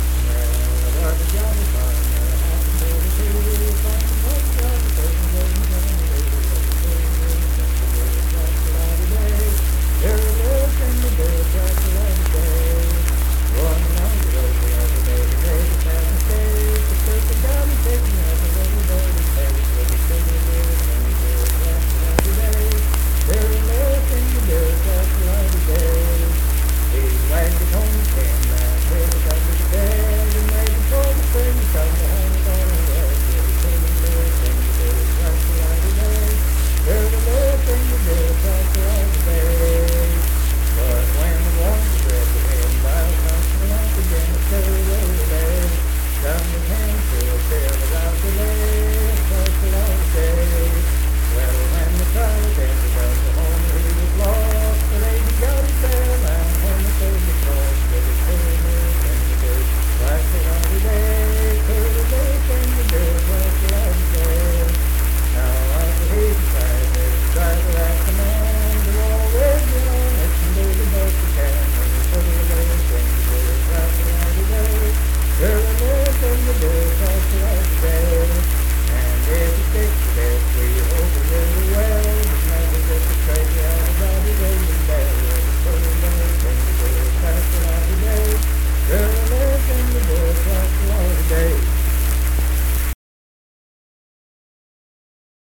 Unaccompanied vocal music
Performed in Kanawha Head, Upshur County, WV.
Voice (sung)